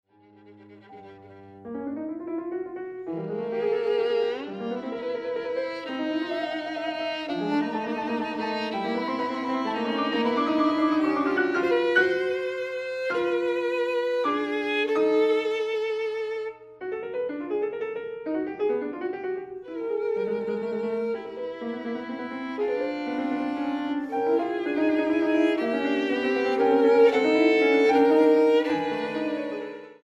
Sonata para violín y piano (2012)